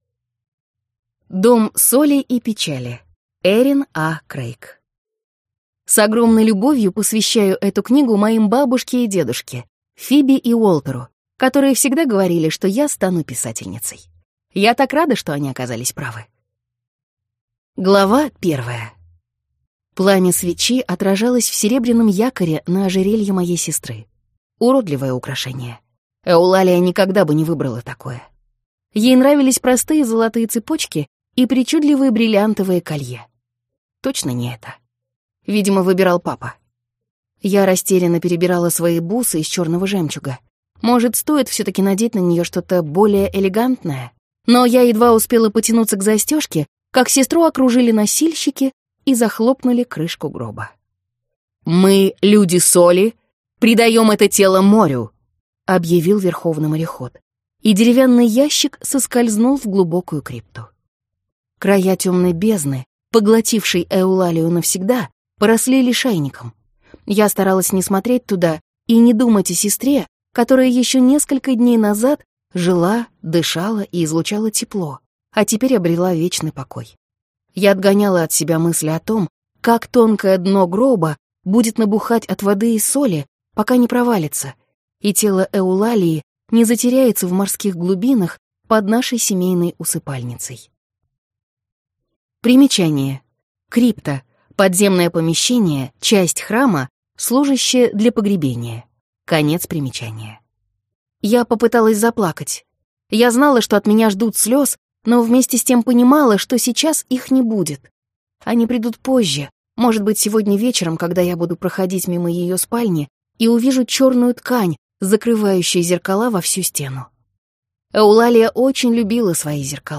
Аудиокнига Дом соли и печали | Библиотека аудиокниг